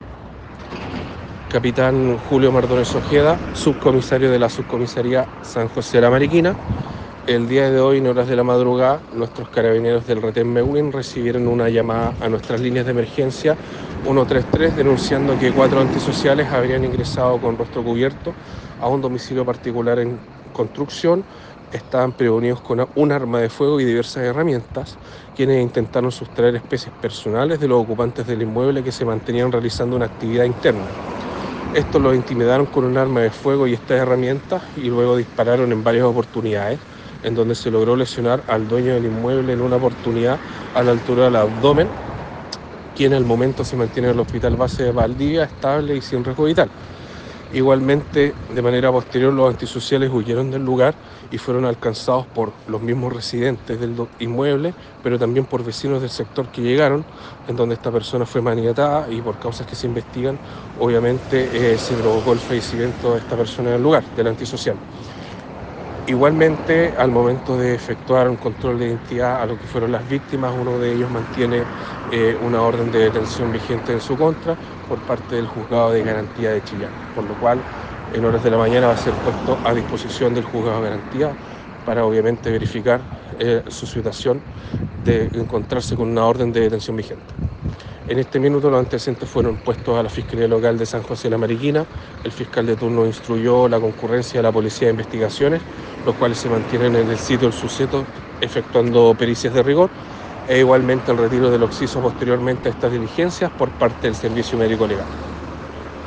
fiscal Gonzalo Valderas, de la Unidad de Flagrancia de la Fiscalía de Los Ríos, con los antecedentes preliminares de este caso y las diligencias instruidas por la Fiscalía.